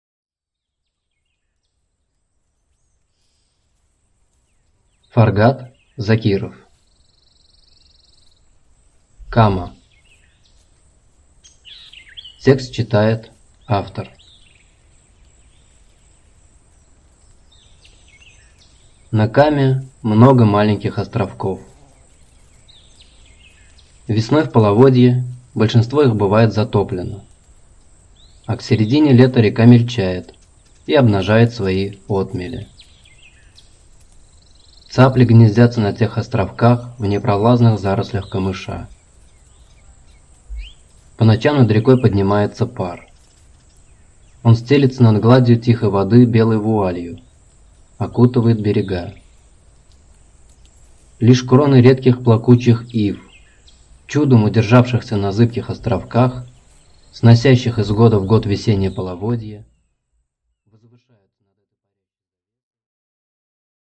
Aудиокнига Кама